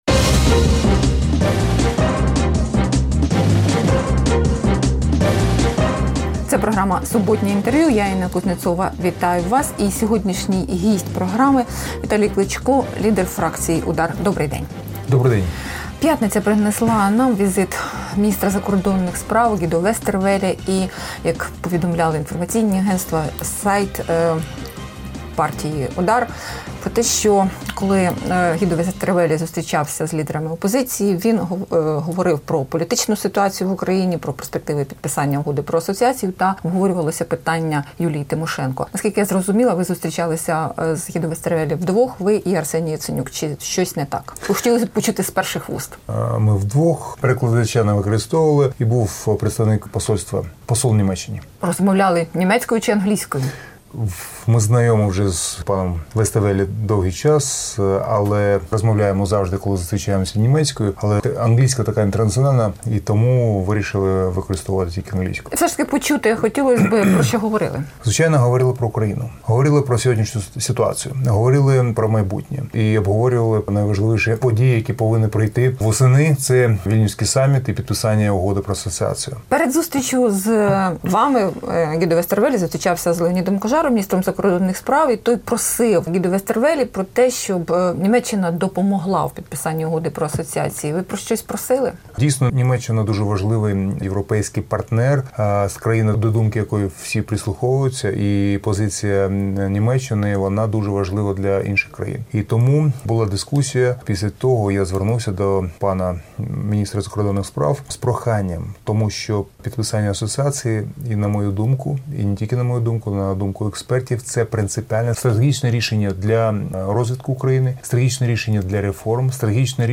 Інтерв’ю з Віталієм Кличком